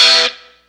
Track 02 - Guitar Stab OS 01.wav